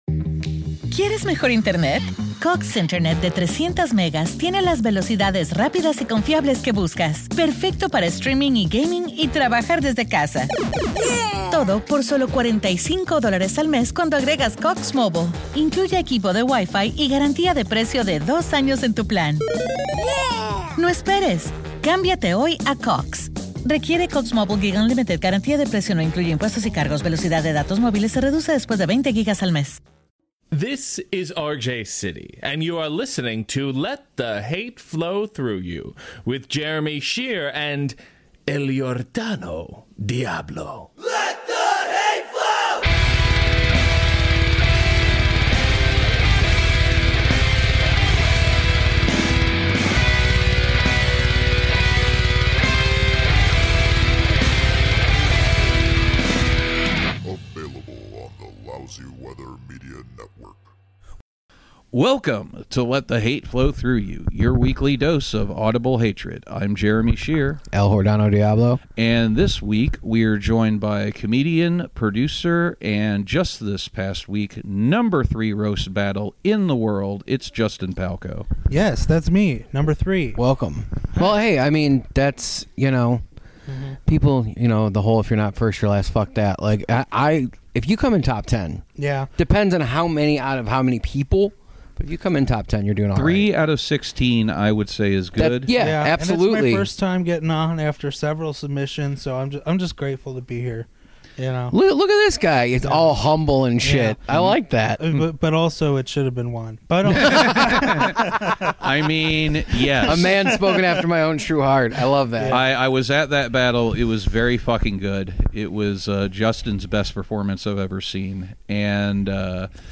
Three fatties who have replaced drugs with snacks hate the sobriety industrial complex for an hour